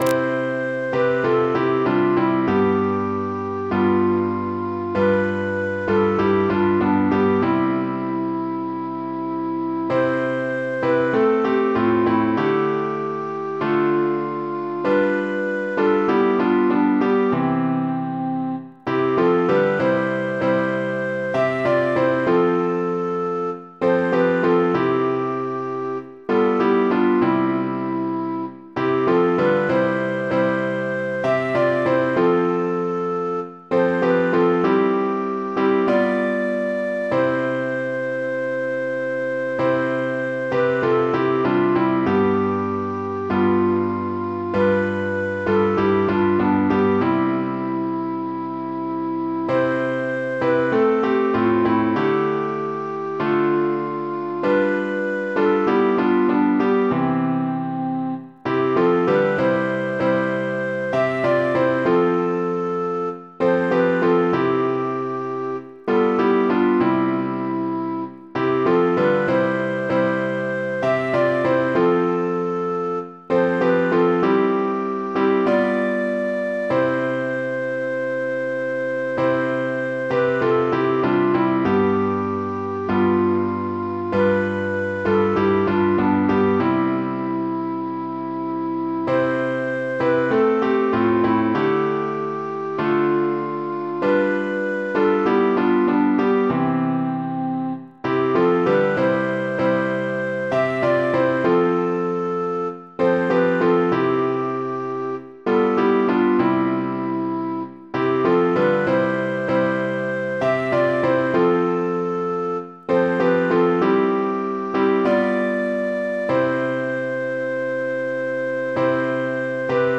piano, keyboard, keys
Мелодия за разучаване: